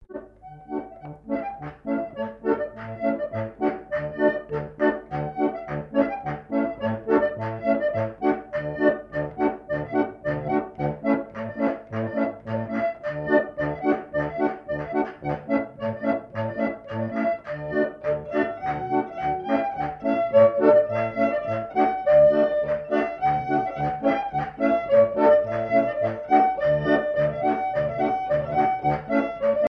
Chants brefs - A danser
danse : gigouillette
Pièce musicale éditée